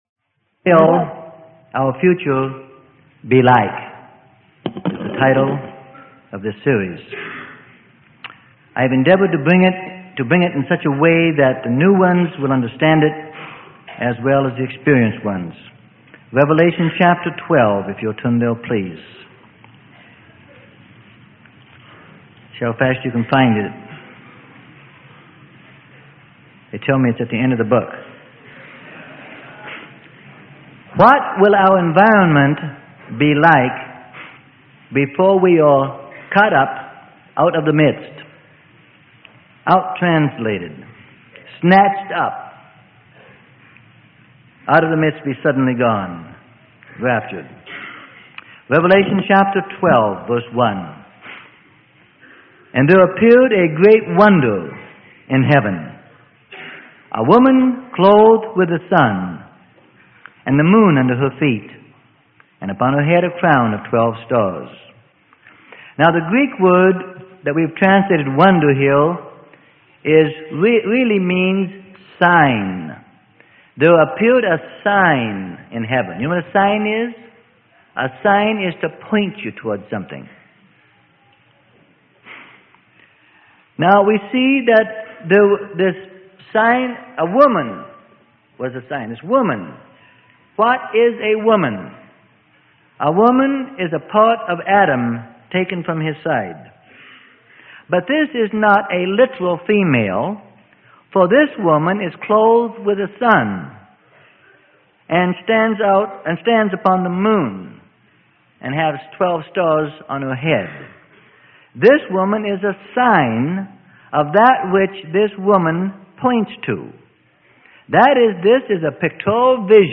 Sermon: What Will Our Future Be Like - Part 01 - Freely Given Online Library